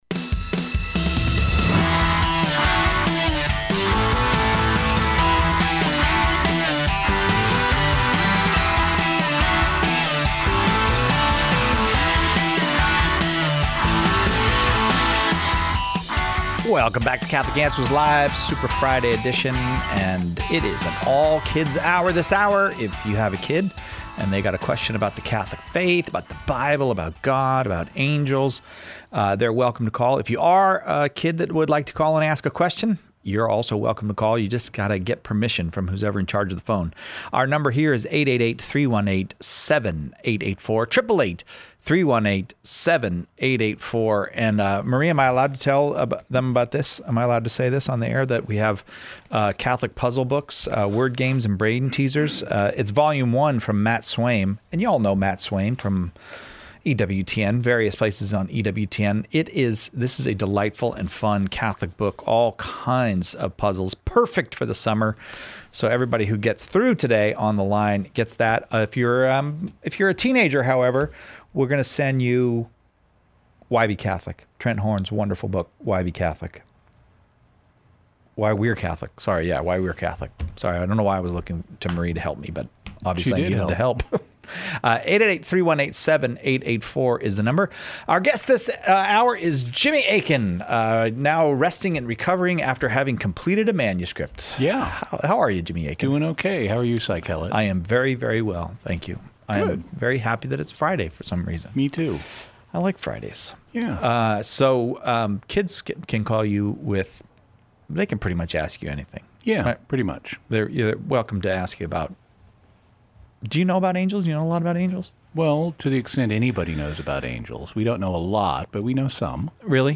Children between the ages of 3 and 16 are welcome to call with their questions about the faith.